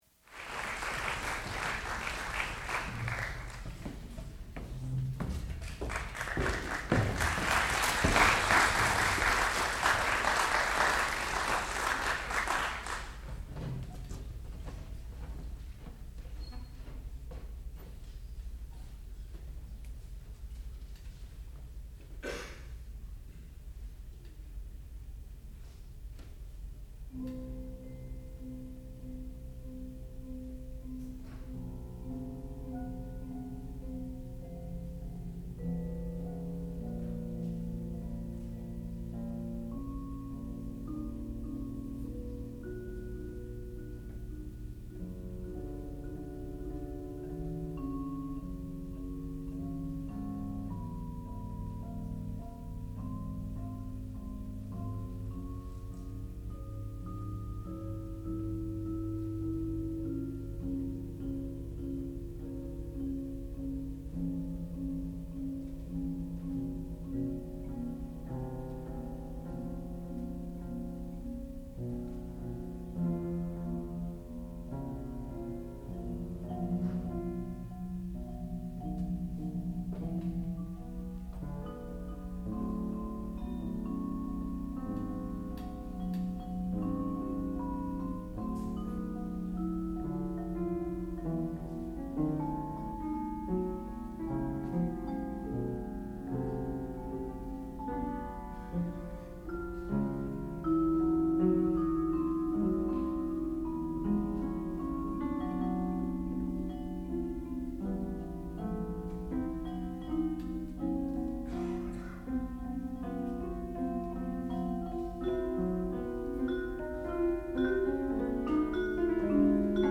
sound recording-musical
classical music
marimba
electric piano